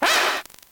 Fichier:Cri 0498 NB.ogg
contributions)Televersement cris 5G.